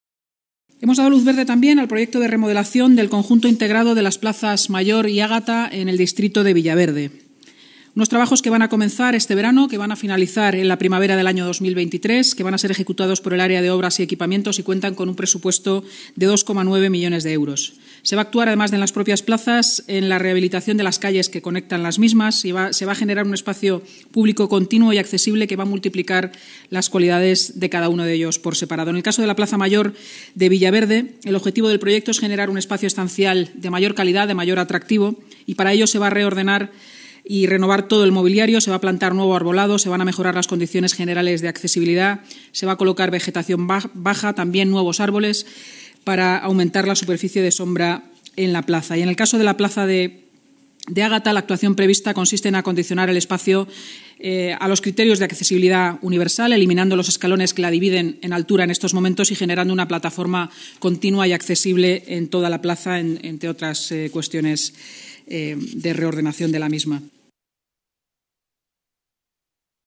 Así lo ha anunciado en rueda de prensa la portavoz municipal, Inmaculada Sanz, tras la reunión semanal de la Junta de Gobierno. Los trabajos, que comenzarán este verano y finalizarán en la primavera de 2023, serán ejecutados por el Área de Obras y Equipamientos y contarán con un presupuesto de 2,9 millones de euros.